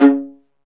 STR VLA MR0V.wav